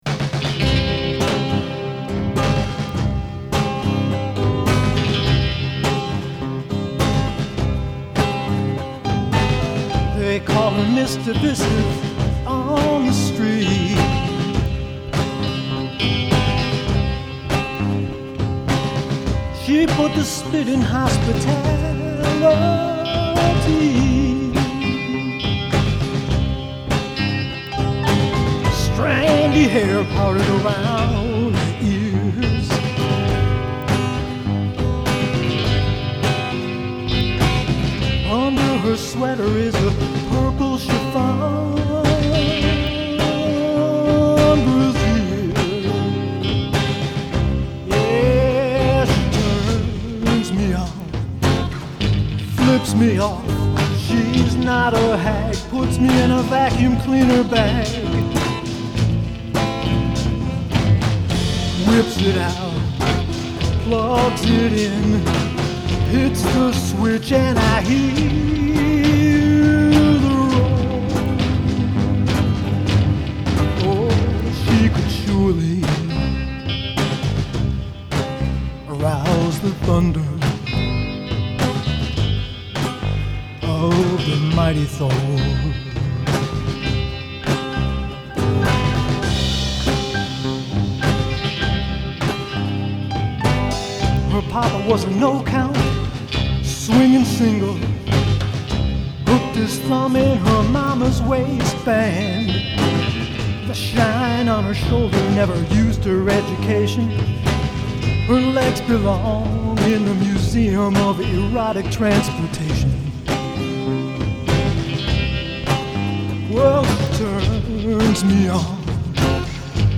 Named for the large diaphram condenser microphone
I started using instead of the Shure 57.